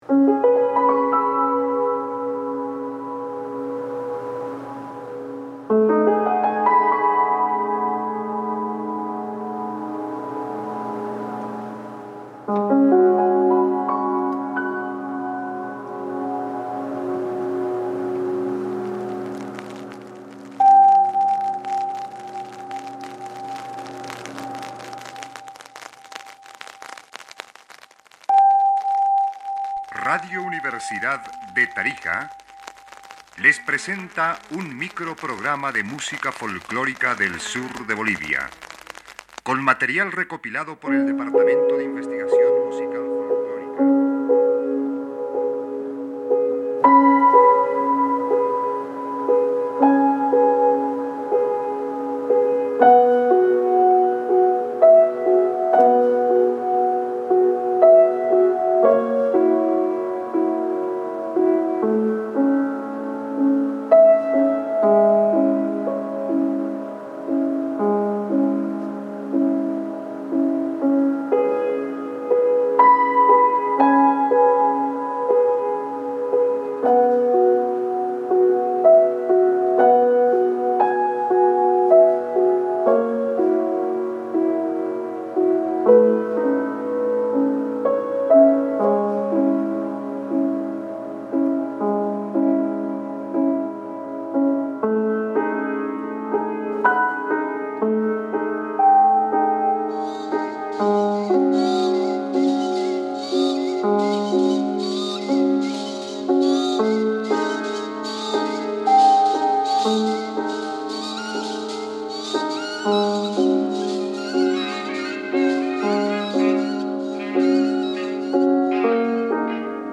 Airwaves Unfolded is a piece based on a radio recording produced in the 1960s in Tarija, southern Bolivia, by Radio Universidad de Tarija. The program introduces Indigenous instruments and discusses how they have been intertwined with labour, daily life, and ritual, as well as how new instruments and musical forms developed after Spanish influence.
What drew me to this recording were the instruments’ unstable yet resonant tones alongside the narrator’s voice. The voice carries a sense of responsibility, warmth, and quiet pride, reflecting an intention to preserve cultural knowledge and pass it on to future generations.